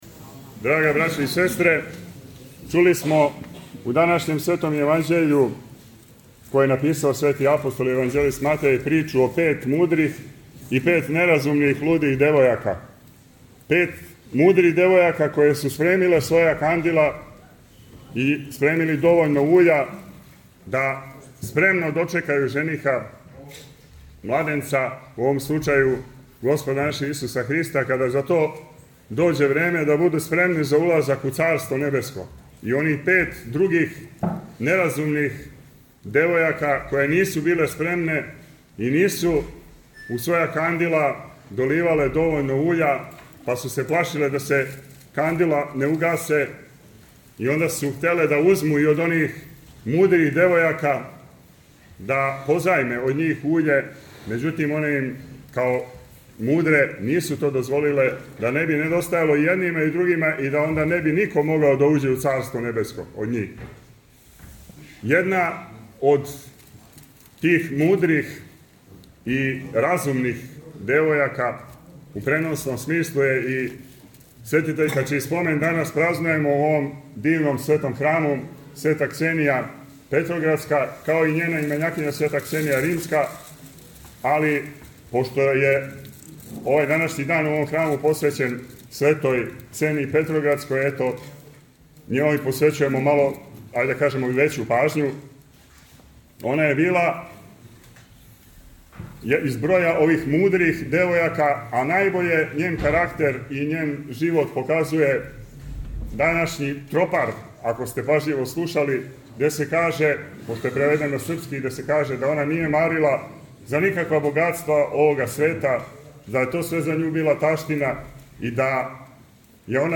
Преподобна мати Ксенија Петроградска је молитвено прослављена у Крушевачком насељу Мудраковац, сазнаје Радио “Слово љубве”.